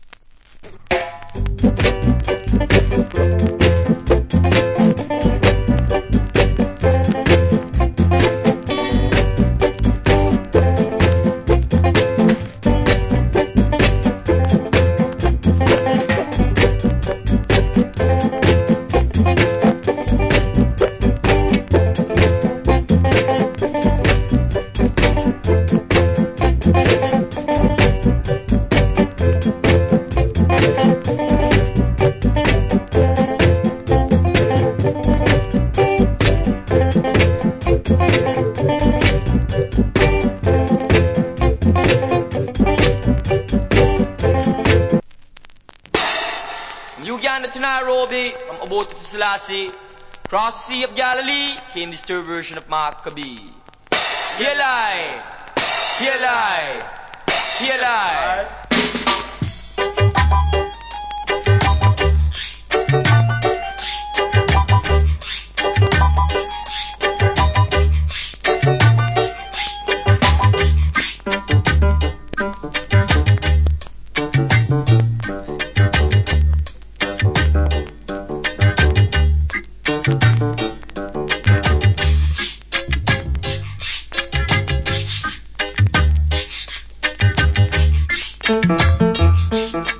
コメント Wicked Early Reggae Inst. / Nice Reggae Inst.